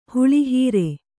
♪ huḷi hīre